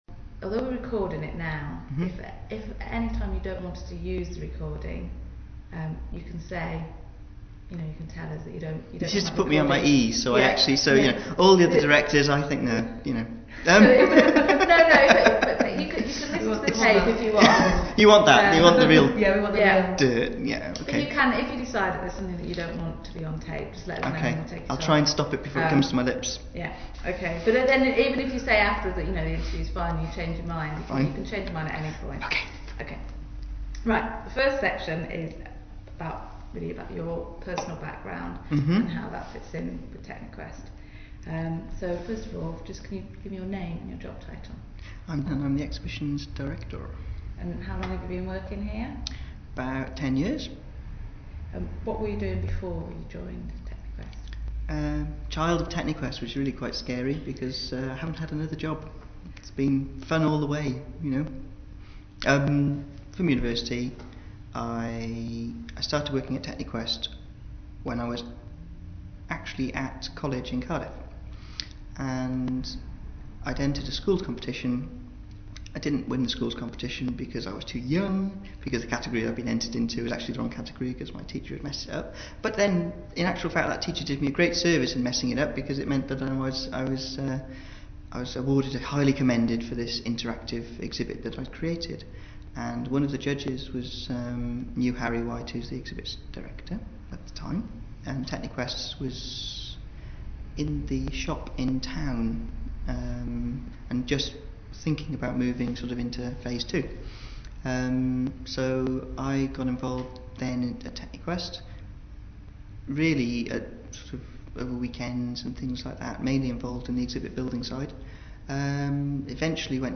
Activity: Face to face interview
Equipment: HHB Portadisc, Beyer Interview mic, Sennheiser K6/ME62 (omni directional mic).
Interview